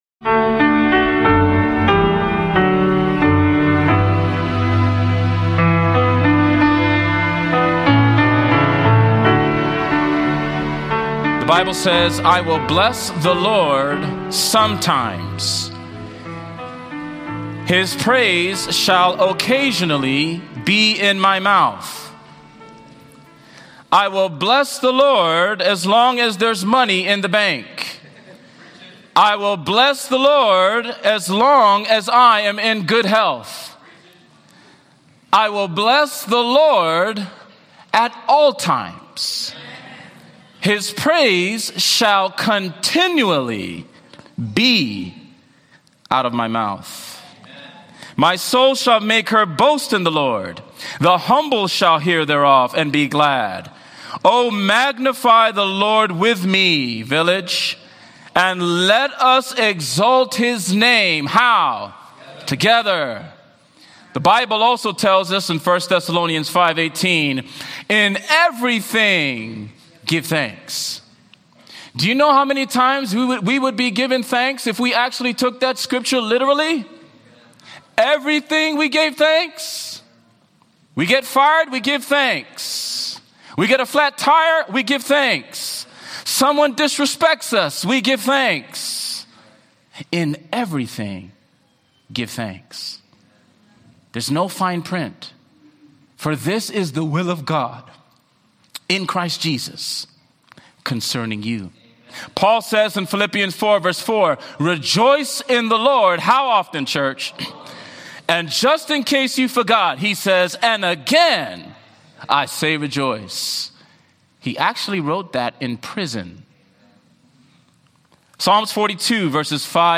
This powerful sermon explores the unchanging nature of God, the transformative power of praise, and the assurance that Christ is always with us, even in the storm.